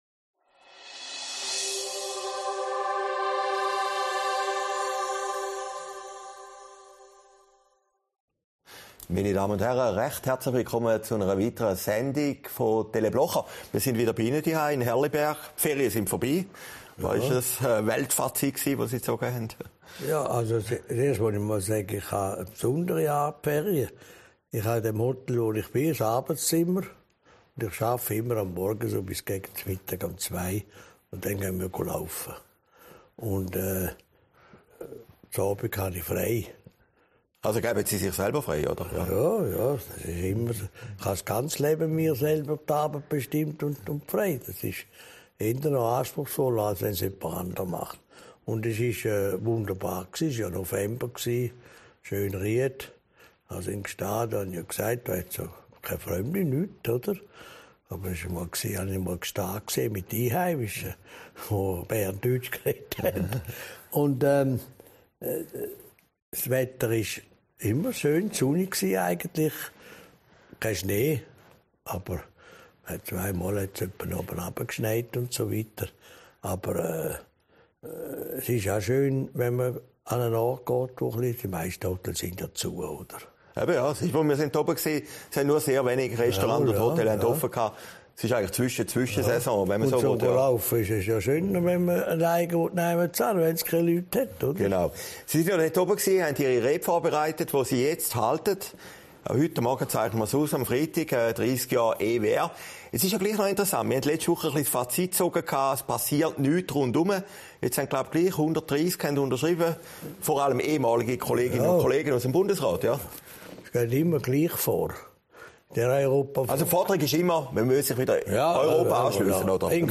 Sendung vom 2. Dezember 2022, aufgezeichnet in Herrliberg